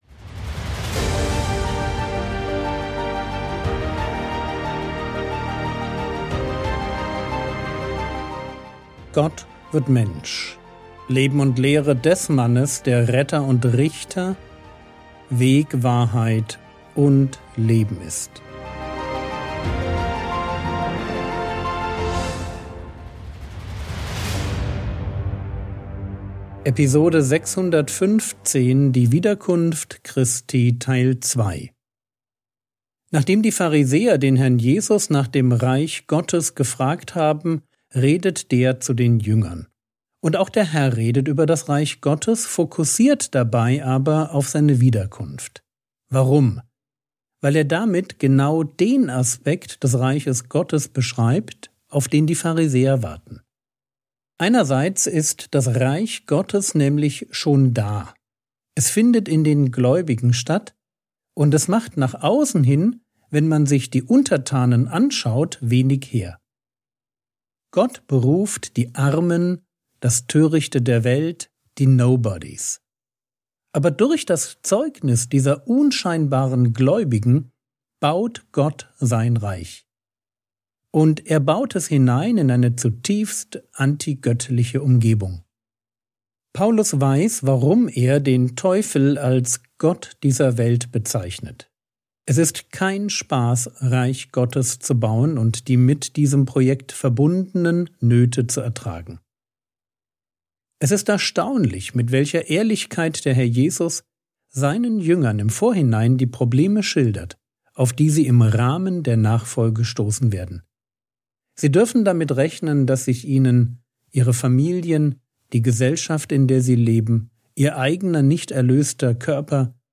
Episode 615 | Jesu Leben und Lehre ~ Frogwords Mini-Predigt Podcast